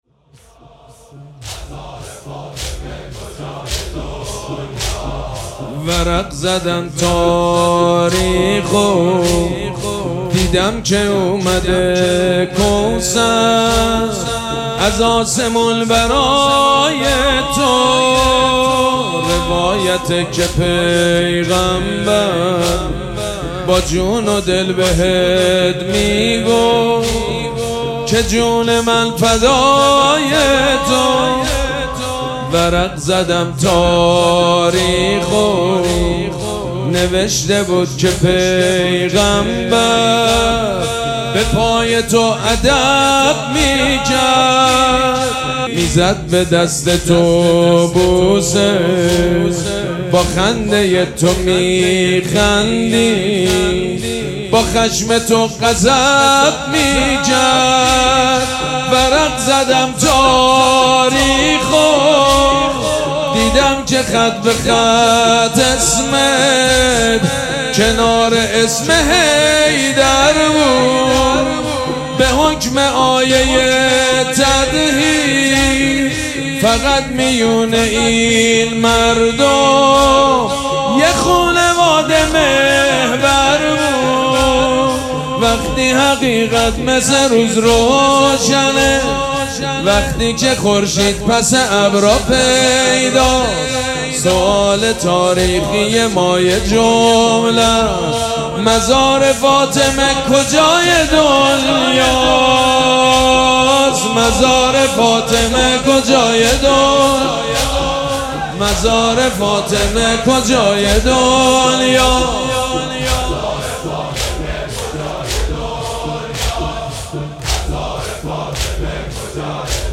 شب اول مراسم عزاداری دهه دوم فاطمیه ۱۴۴۶
حسینیه ریحانه الحسین سلام الله علیها
مداح
حاج سید مجید بنی فاطمه